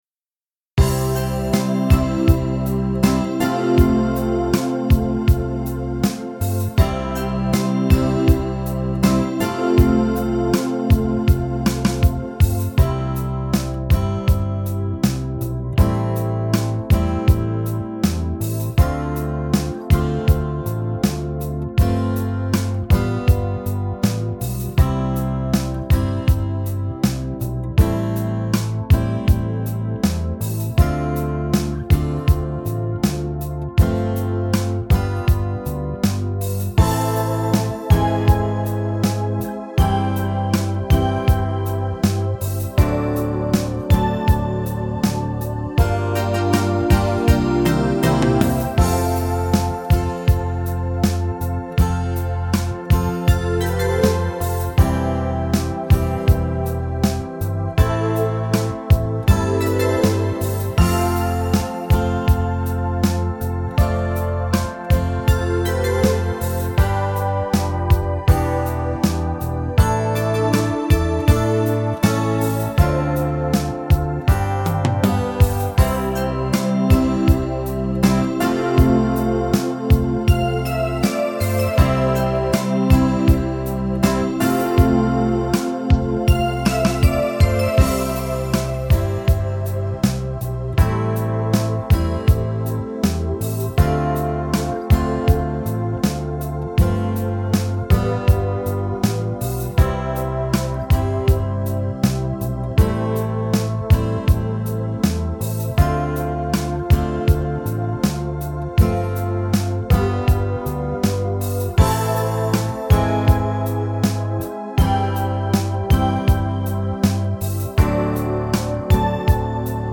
караоке
минусовка